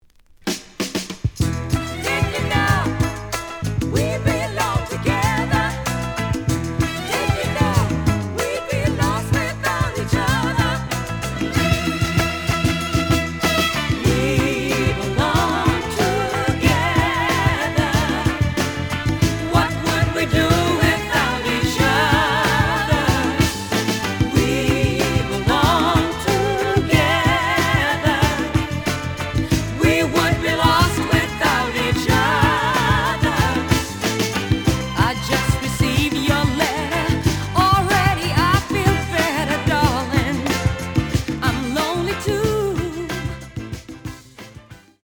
The audio sample is recorded from the actual item.
●Genre: Soul, 70's Soul
Slight damage and writing on both side labels. Plays good.)